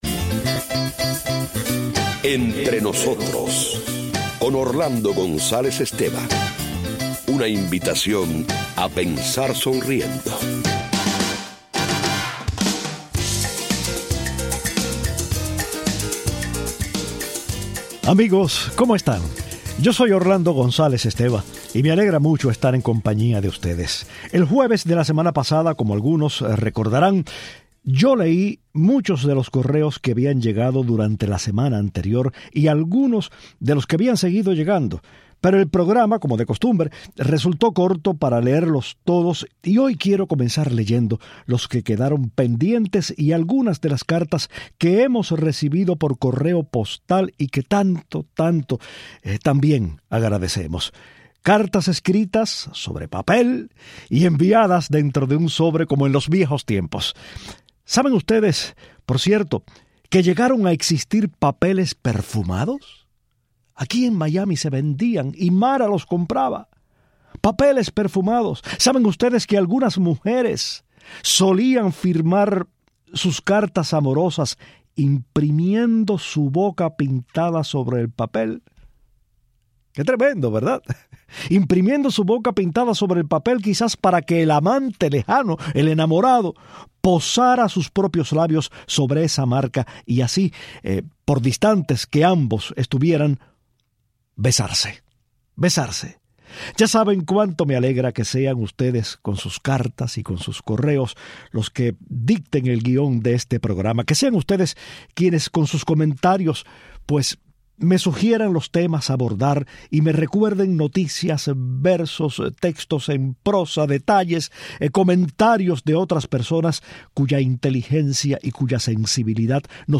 Versos, correos electrónicos y llamadas de los oyentes dictan el guión de este programa.